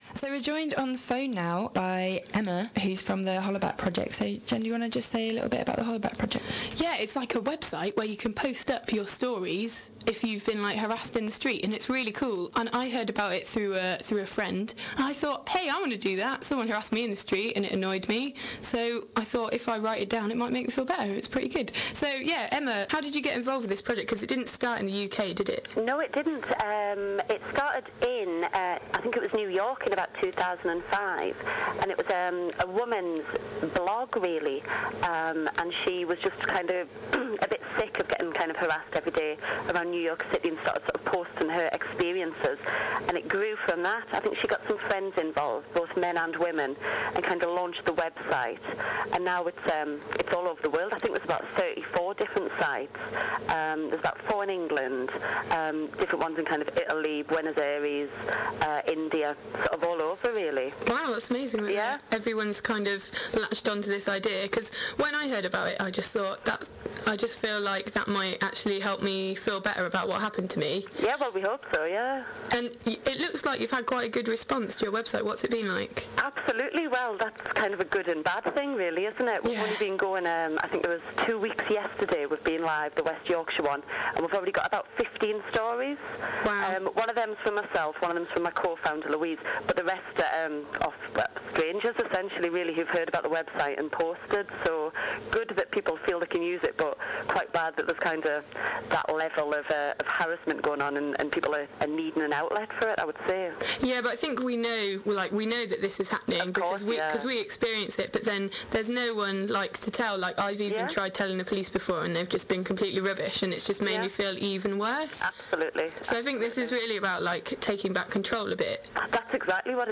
hollabackinterview2011.mp3